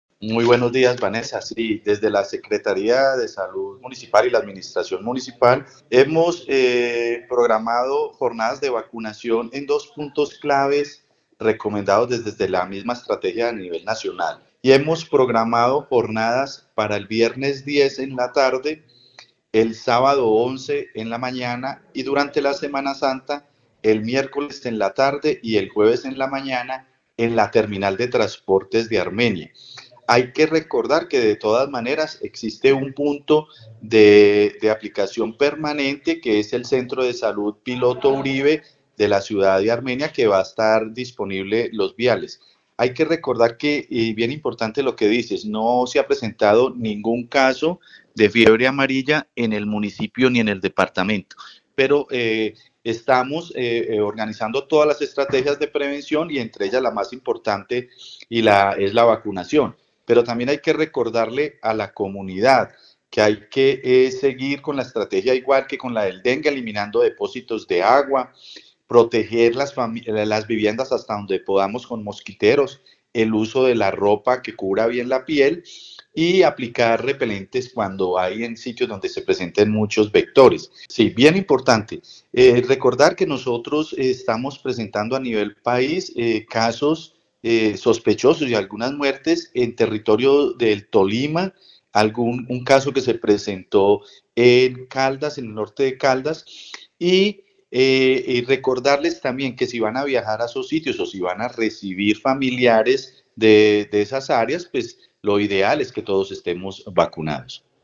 Secretario de Salud de Armenia